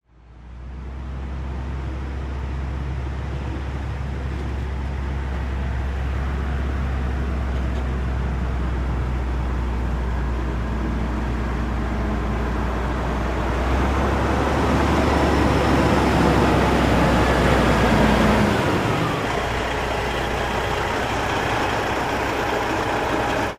tr_dieseltruck_stop_01_hpx
Diesel truck drives up and stops and idles, and passes over railroad tracks. Vehicles, Truck RR Crossing Engine, Motor